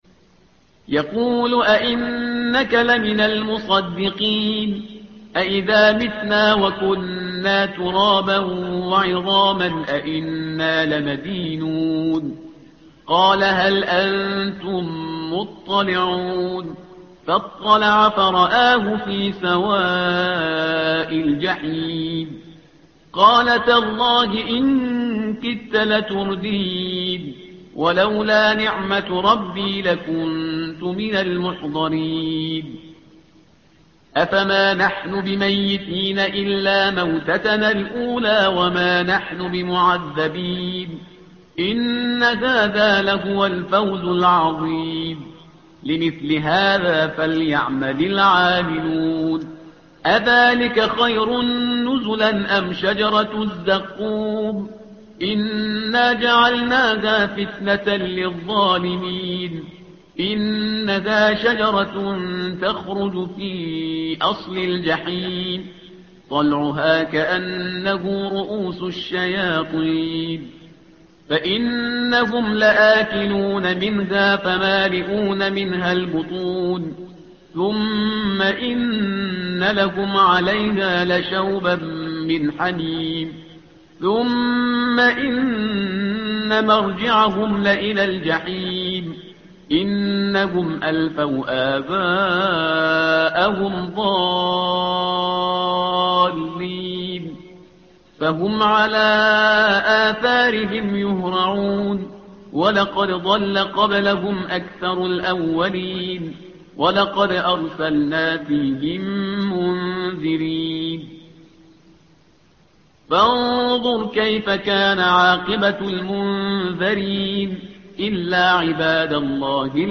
الصفحة رقم 448 / القارئ